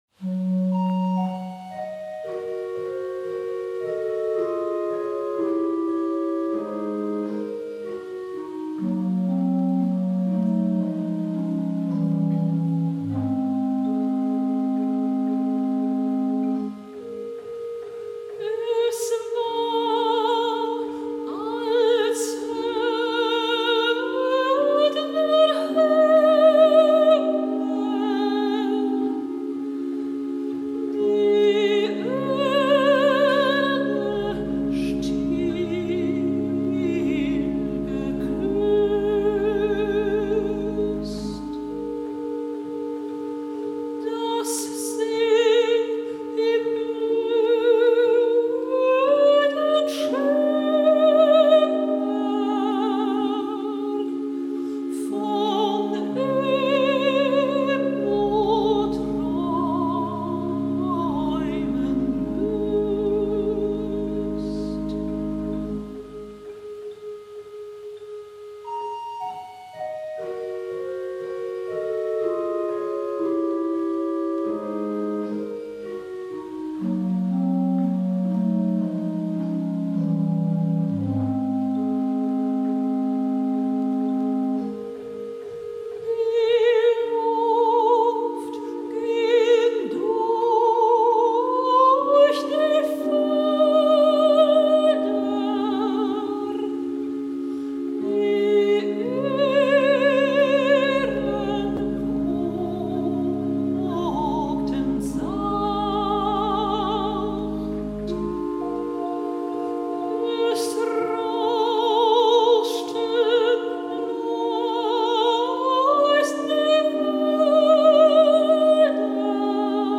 Hochzeitssängerin Hannover/Niedersachsen
Eine brilliante, warme Stimme soll dazu beitragen Ihrer Zeremonie einen feierlichen Rahmen zu geben.